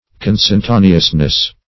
Meaning of consentaneousness. consentaneousness synonyms, pronunciation, spelling and more from Free Dictionary.
consentaneousness.mp3